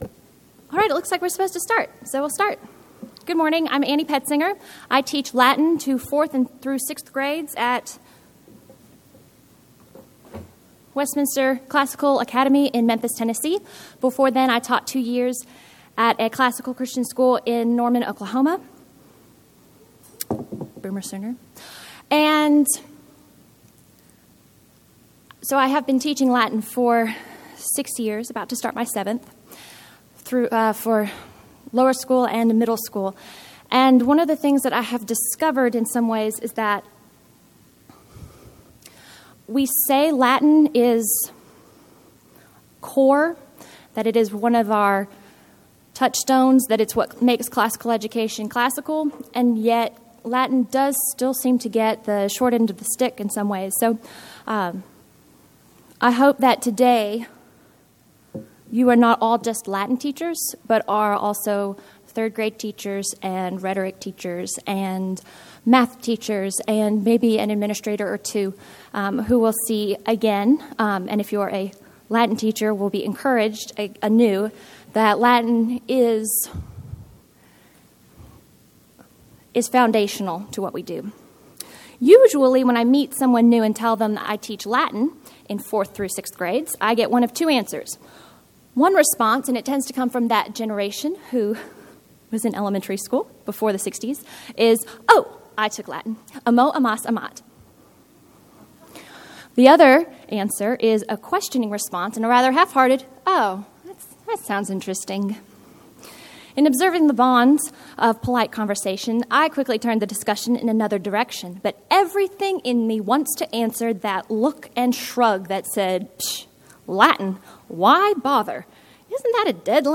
2012 Workshop Talk | 0:40:16 | K-6, Latin, Greek & Language
Speaker Additional Materials The Association of Classical & Christian Schools presents Repairing the Ruins, the ACCS annual conference, copyright ACCS.